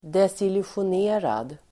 Ladda ner uttalet
Uttal: [desilusjån'e:rad]